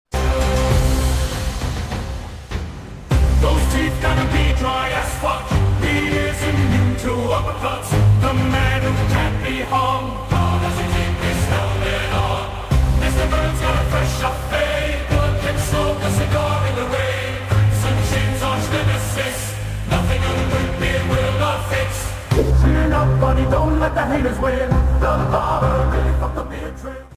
Instead, it explores the wild and unfiltered world of Instagram comments, showcasing the funny, bizarre, and unexpected things people say online. By transforming these comments into music, the goal is to highlight how digital spaces shape humor and communication, not to disrespect or dehumanize individuals.